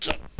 spearman_attack9.wav